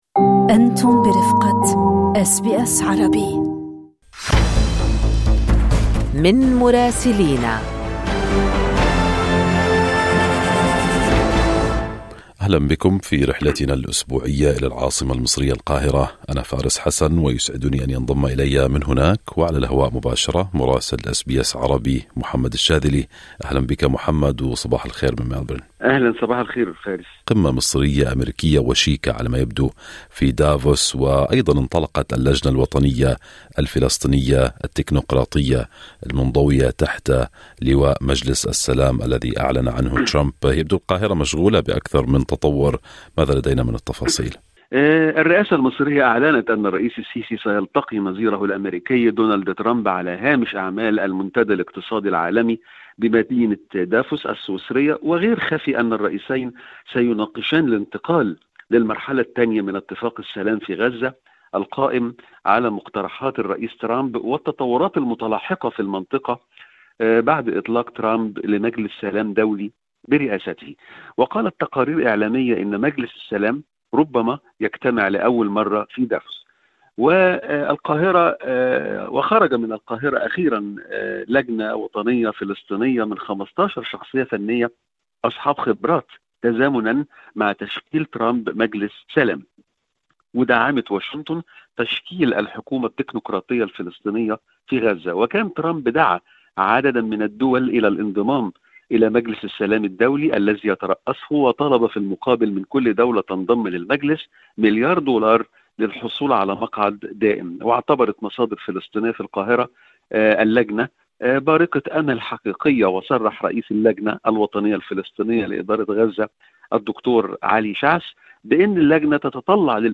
من مراسلينا: قمة أمريكية مصرية في دافوس..